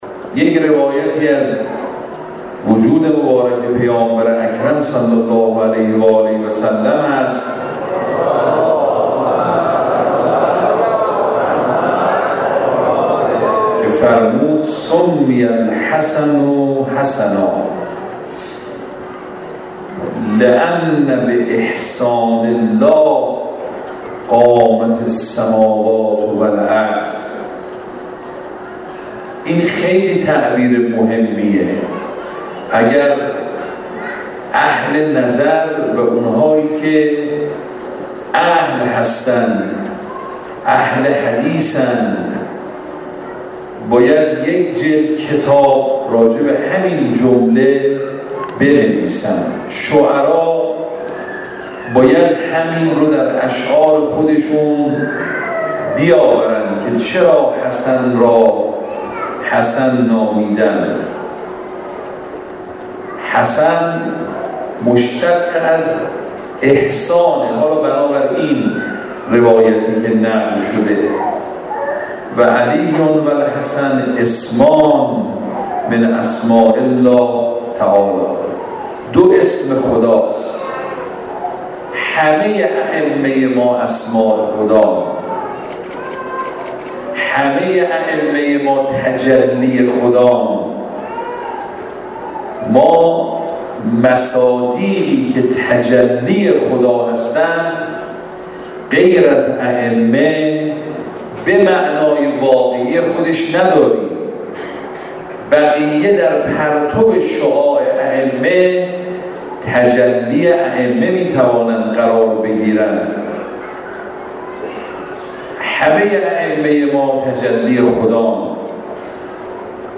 به گزارش خبرنگار سیاسی خبرگزاری رسا، آیت الله محمد جواد فاضل لنکرانی عضو جامعه مدرسین حوزه علمیه قم امشب در سوگواره شعر امام حسن مجتبی(ع) با عنوان «حماسه صبر» که در سالن همایش های مرکز فقهی ائمه اطهار(ع) برگزار شد، گفت: بر اساس روایات، امام حسن مجتبی(ع) نخستین کسی هستند که دو نور نبوت و امامت در وجود ایشان جمع شده است.